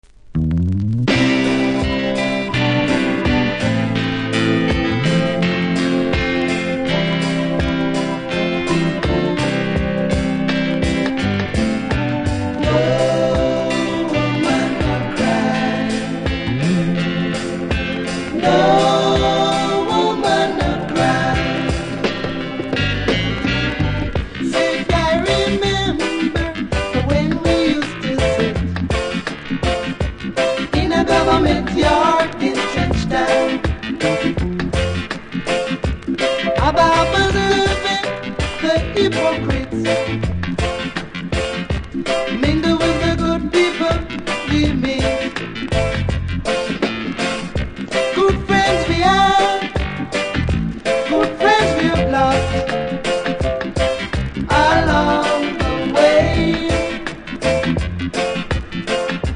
キズそこそこありますがノイズは少なく気にならない程度。